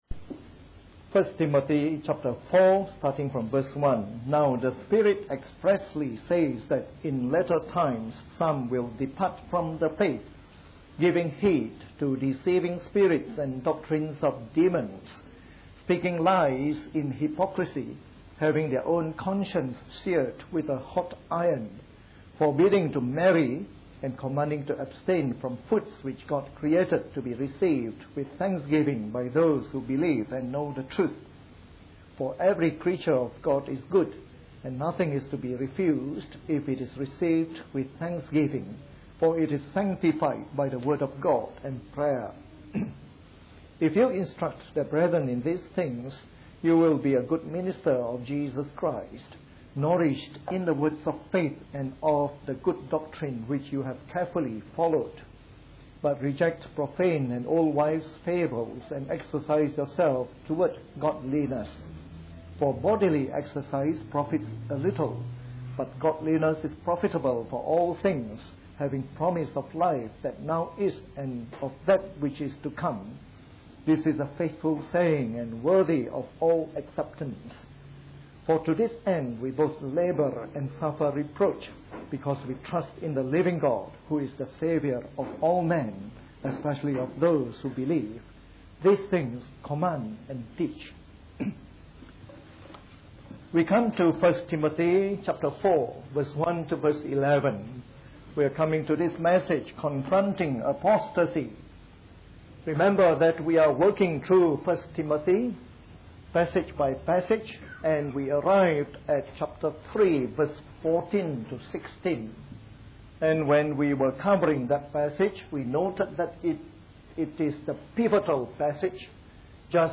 A sermon in the morning service from our new series on 1 Timothy.